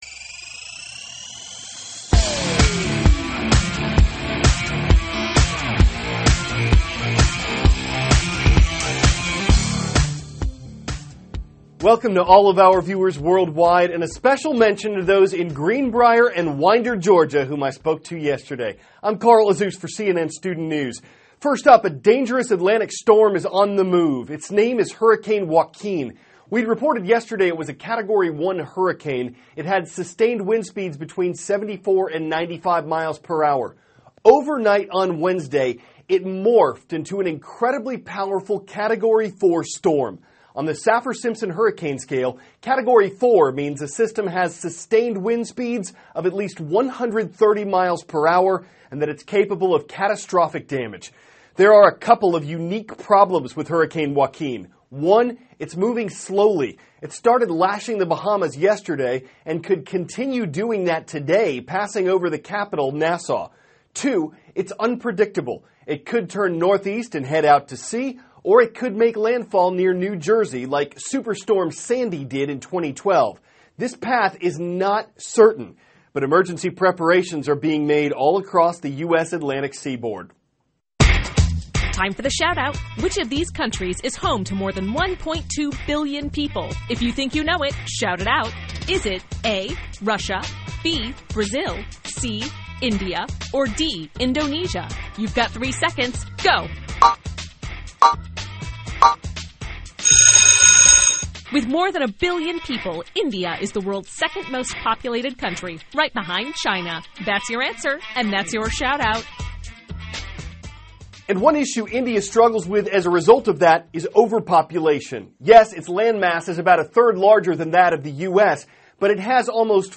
*** CARL AZUZ, cnn STUDENT NEWS ANCHOR: Welcome to all of our viewers worldwide and special mention to those in Greenbrier and Winder, Georgia, whom I spoke to yesterday.